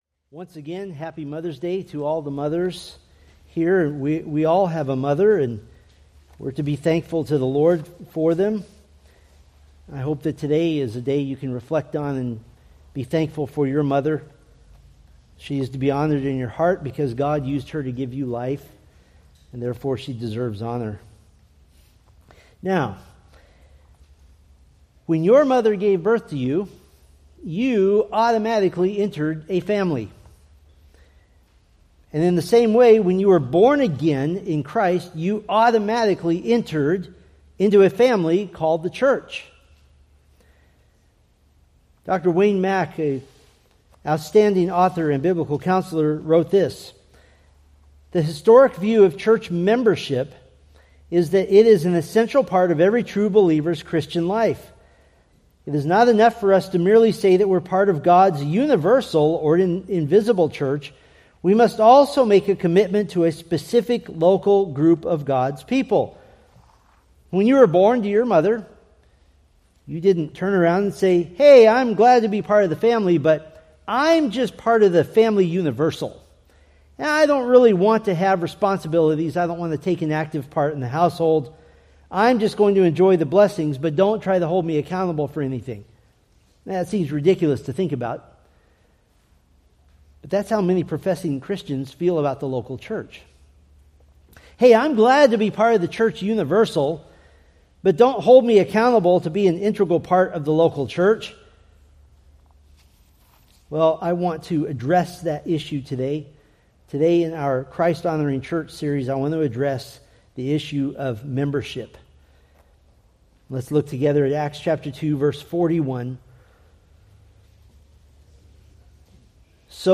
Acts Sermon Series